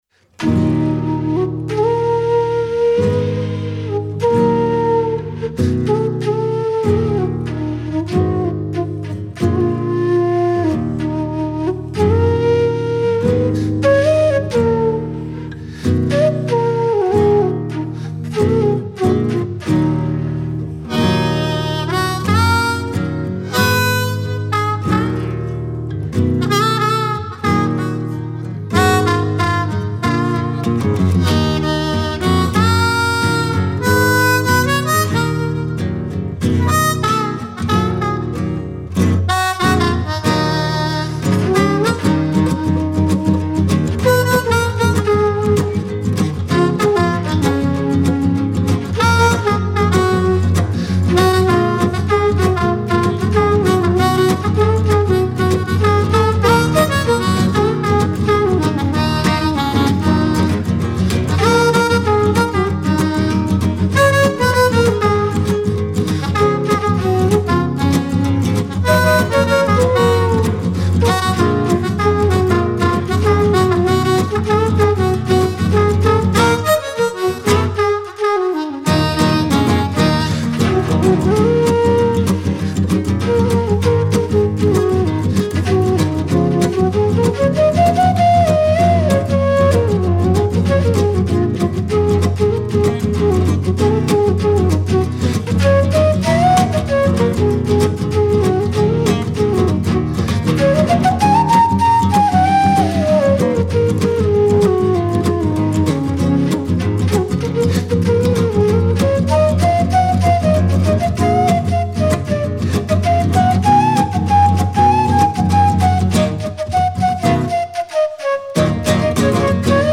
Genre: Acoustic World Music.